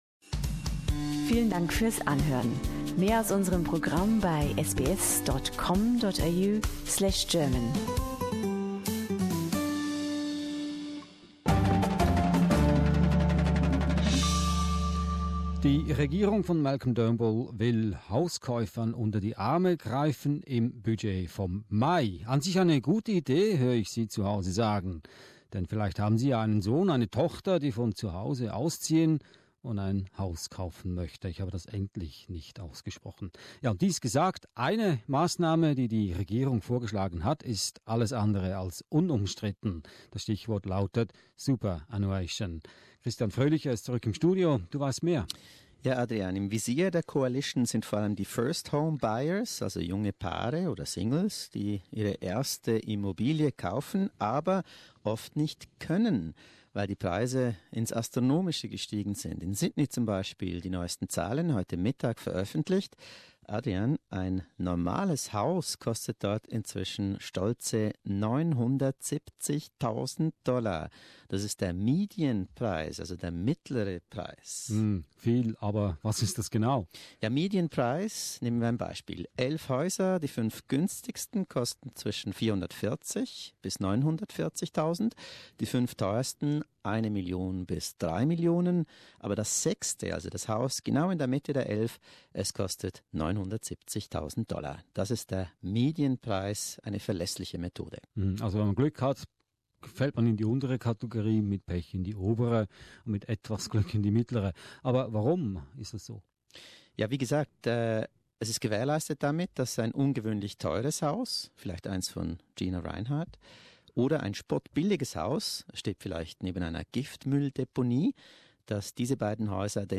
Mehr dazu, in einem SBS Moderationsgespräch.